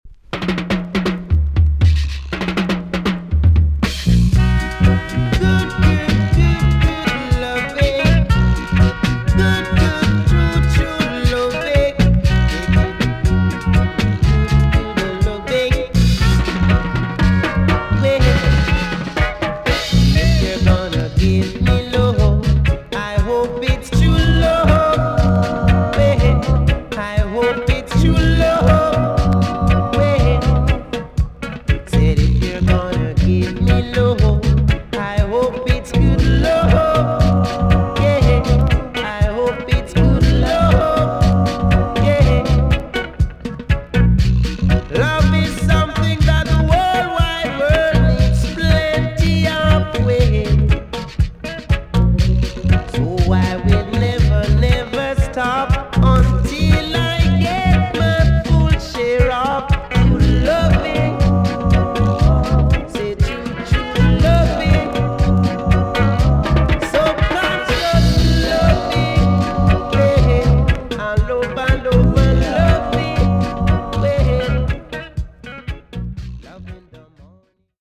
TOP >REGGAE & ROOTS
B.SIDE Version
EX- 音はキレイです。